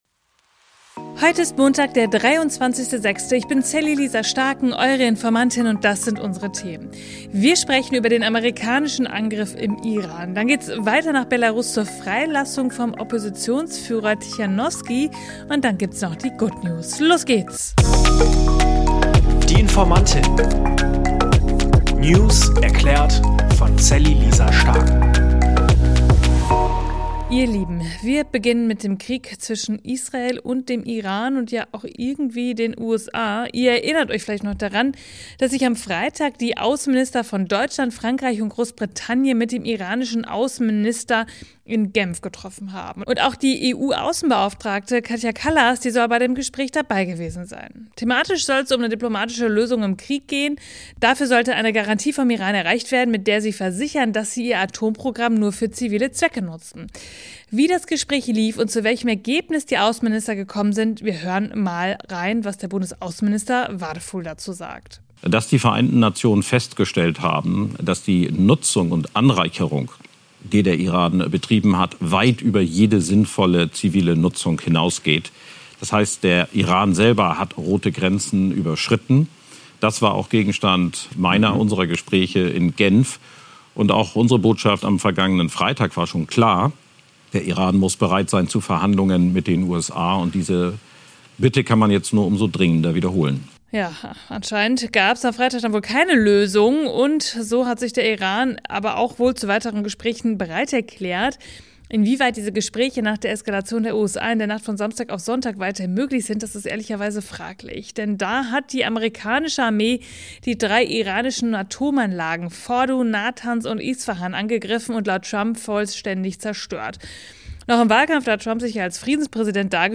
Mit ihrer ruhigen und verständlichen Art